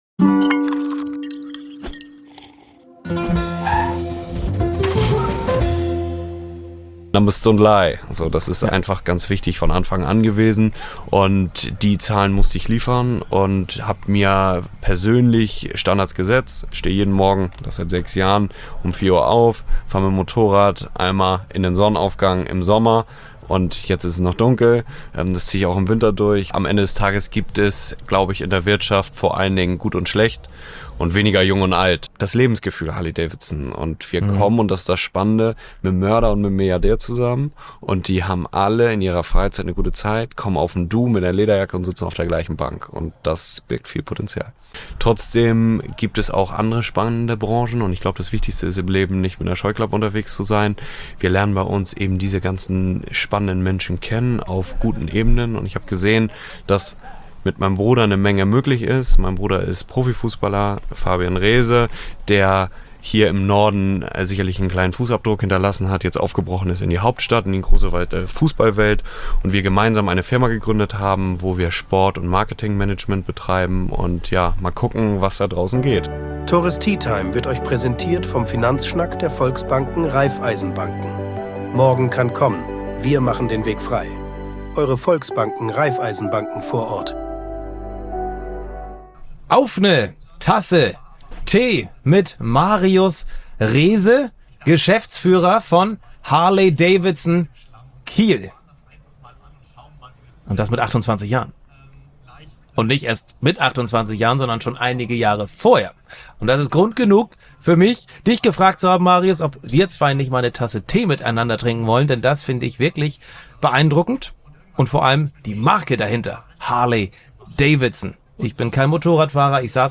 Freut euch auf ein Gespräch über Fokussierung und Prioritäten